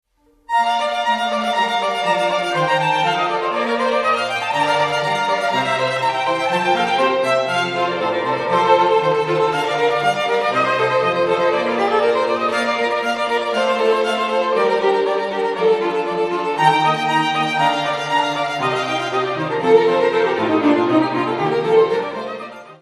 Festliche Streicherklänge zur Zeremonie
(Besetzung D: Streichquartett, SolosängerIn und Orgel)